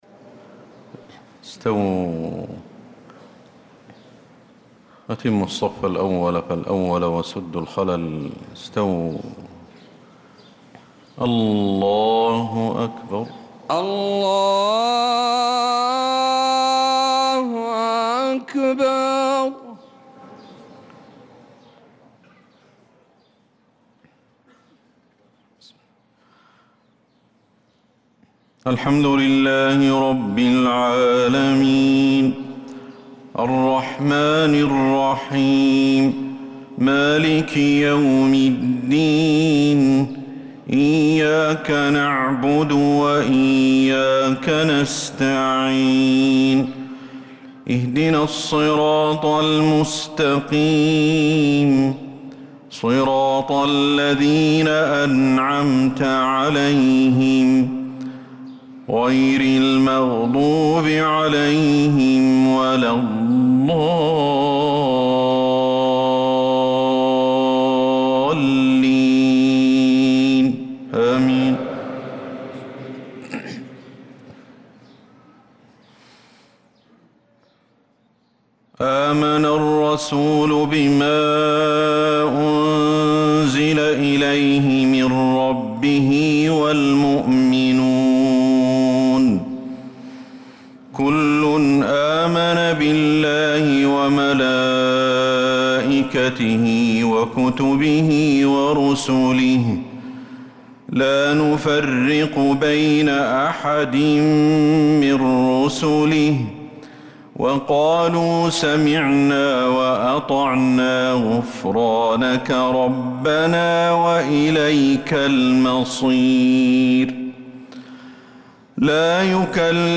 مغرب الأربعاء 1-7-1446هـ خواتيم سورتي البقرة 285-286 و آل عمران 196-200 | Maghreb prayer from Surah al-Baqarah and Aal-i-Imraan 1-1-2025 > 1446 🕌 > الفروض - تلاوات الحرمين